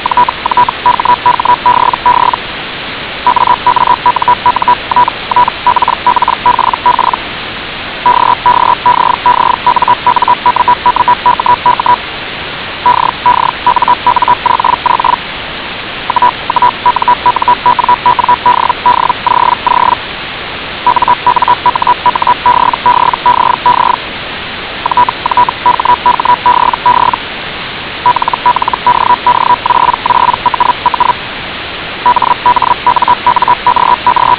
All files are 8 kHz mono, ~34 seconds, generated by HellGen.
980 Hz with 10 dB SNR — realistic noisy conditions537 KB
hell_qbf_980hz_snr10.wav